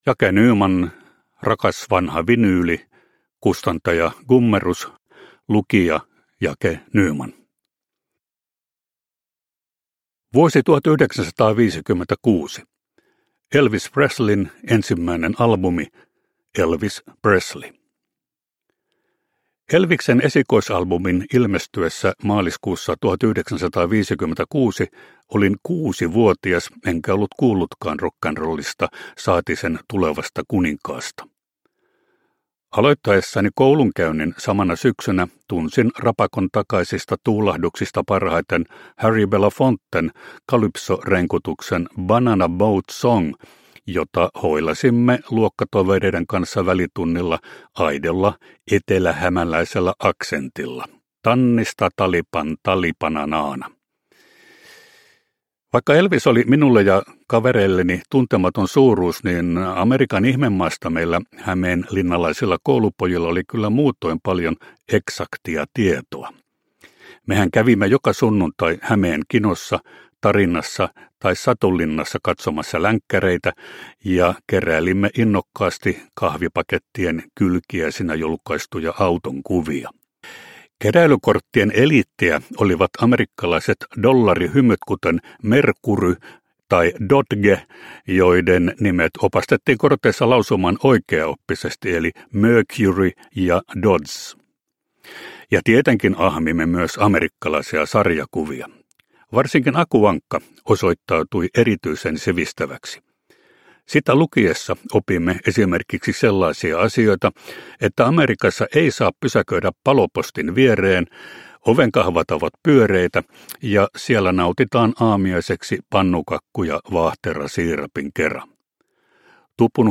Rakas vanha vinyyli – Ljudbok – Laddas ner
Rakas vanha vinyyli on rakkaudentunnustus vinyylilevyille ja kertomus Jake Nymanin elämänmittaisesta löytöretkestä musiikkiin. Unohtumaton radioääni esittelee populaarimusiikin klassikoita ja albumitaiteen kulmakiviä.
Uppläsare: Jake Nyman